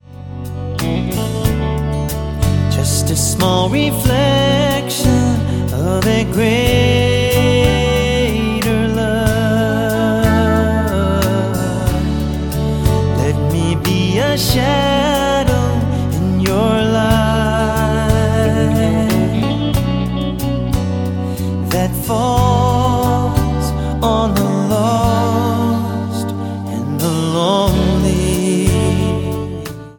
Un'album live